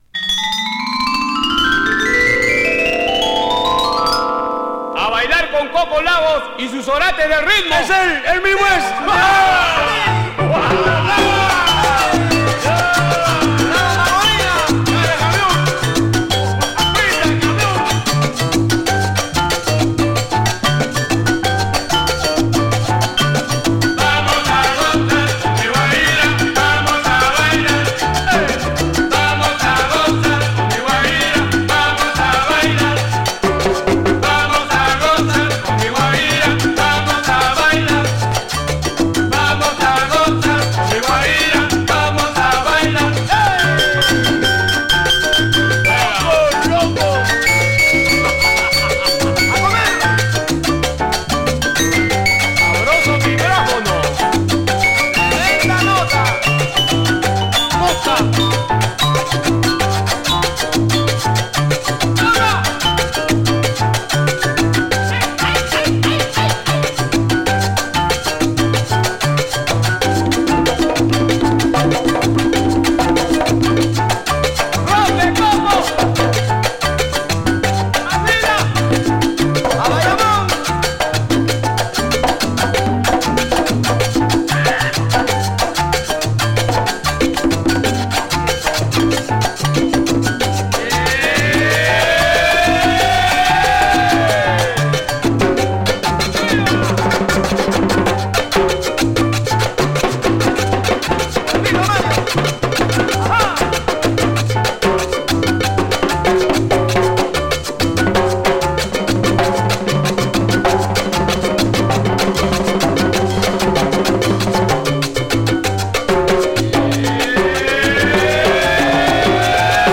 very gritty hard-edged sounds from South America